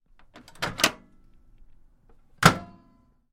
微波炉开闭；2
描述：微波打开和关闭。